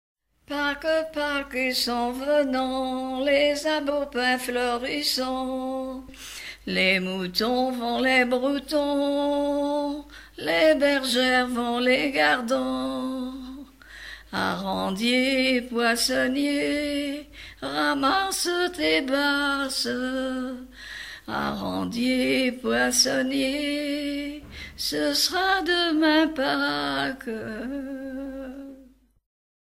Thème : 0124 - Chants brefs - Mardi-Gras Résumé : Pâques s'en venant, les aubépins fleurissant, les moutons vont les broutant, les bergères vont les gardant.
Genre brève